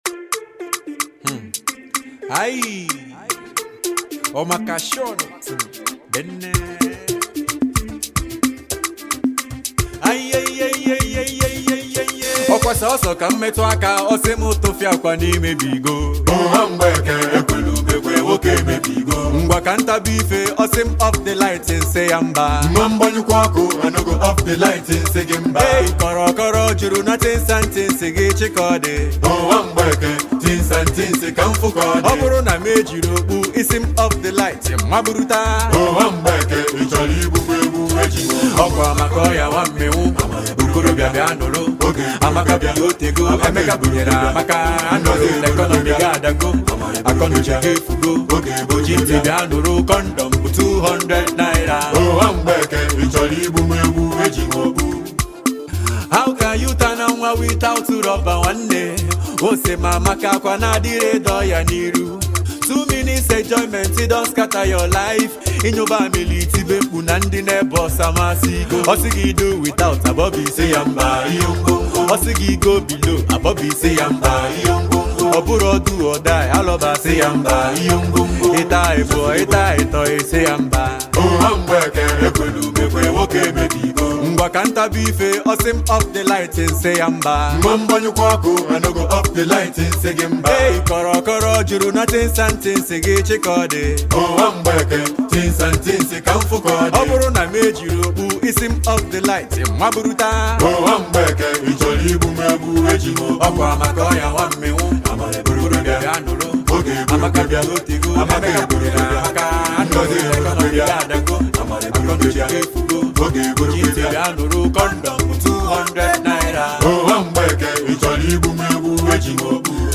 rap phenomenon from SouthEastern Part of Nigeria